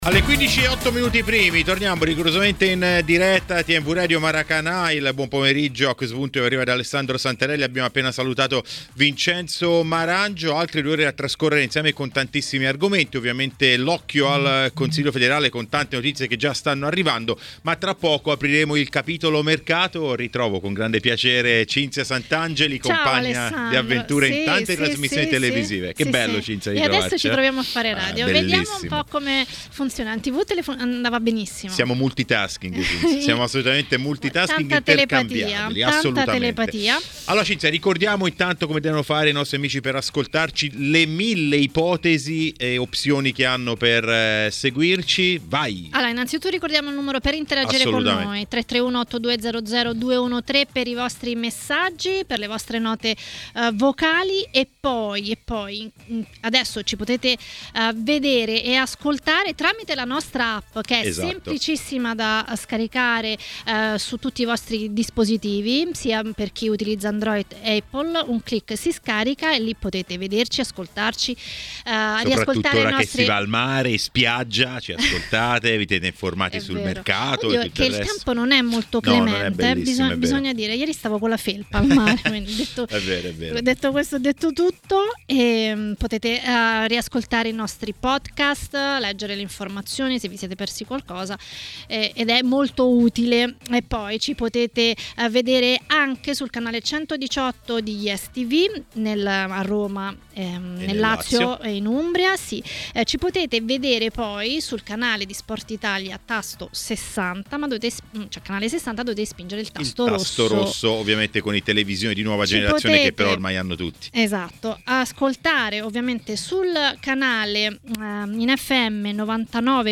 Gianni Di Marzio ha commentato le notizie di mercato nel corso di Maracanà, nel pomeriggio di TMW Radio.